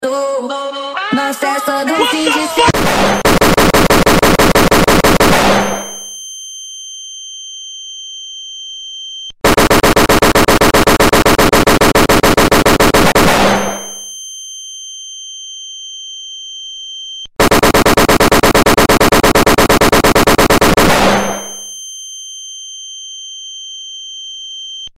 Boom sound effects free download